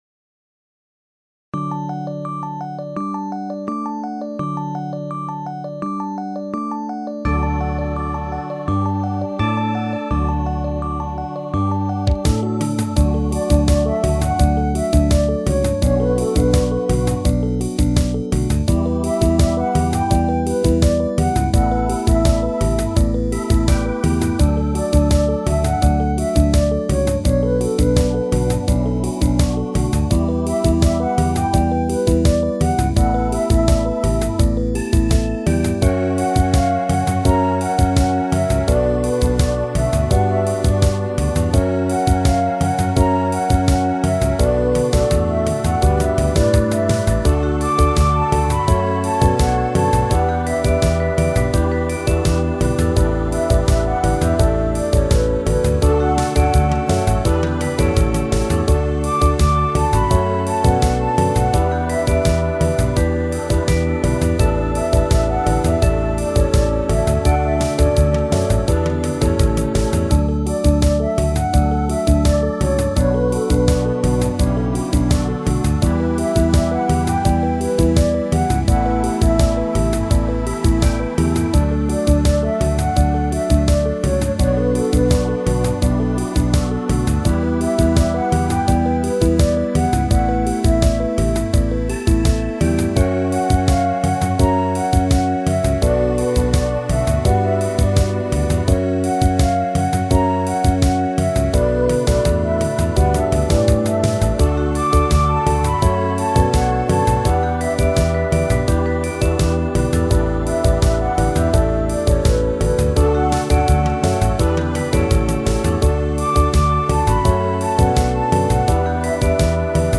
軽いめのライブ調のアレンジ。